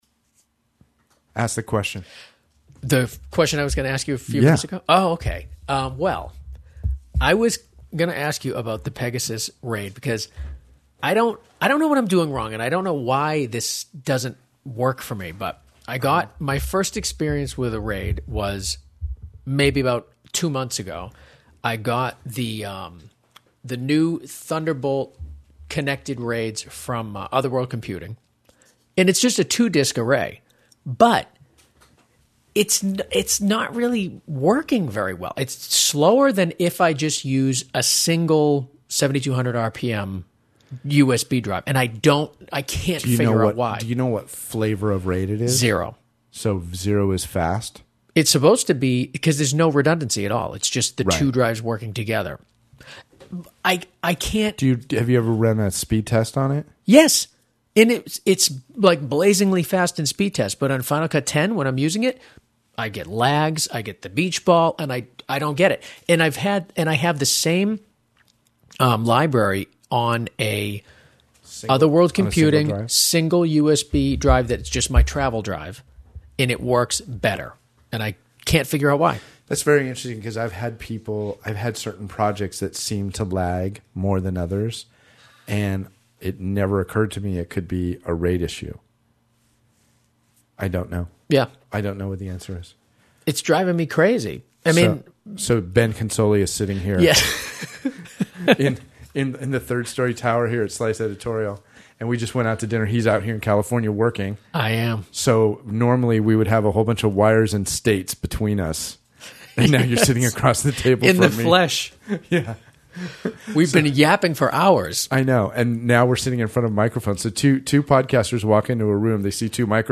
What happens when two podcasters walk into a room with mics on stands and headphones on the table.